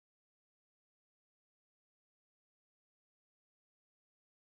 blank.mp3